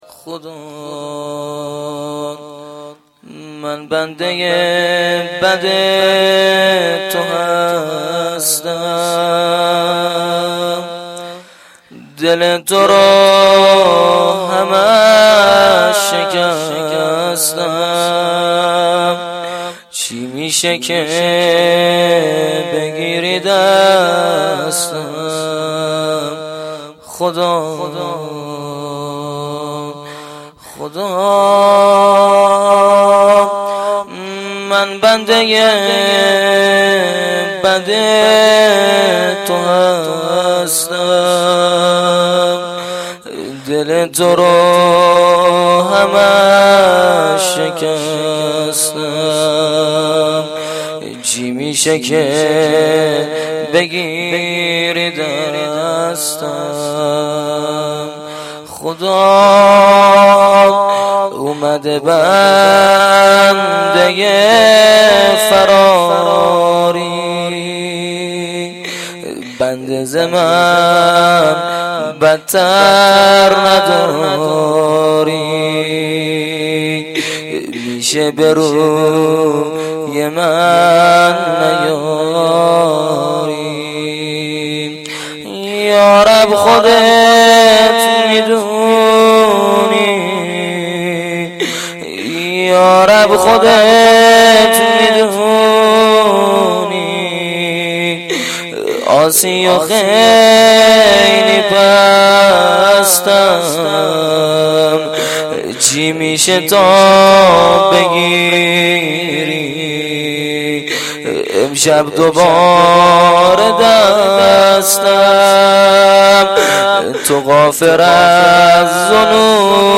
روضه
roze-Rozatol-Abbas-Ramezan93-sh2.mp3